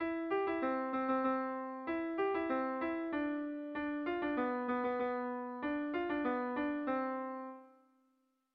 Irrizkoa
Lauko txikia (hg) / Bi puntuko txikia (ip)
AB